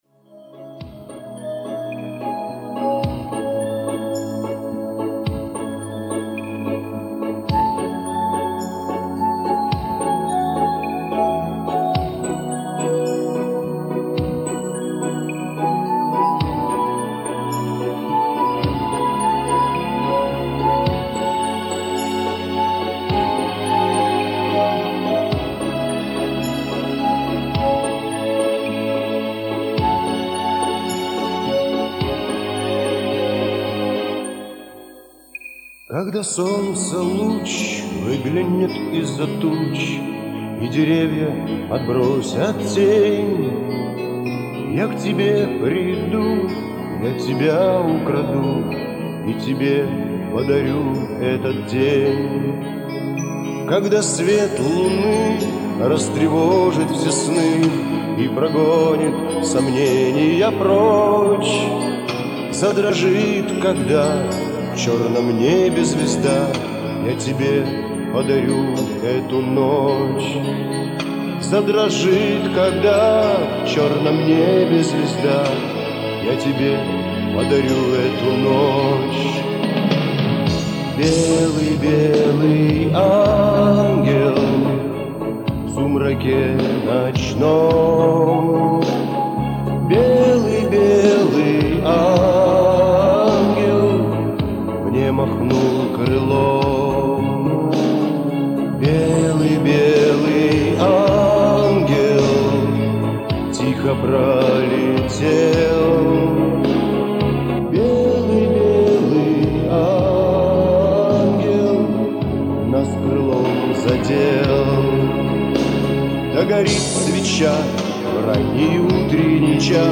писатель, музыкант, автор-исполнитель русского шансона.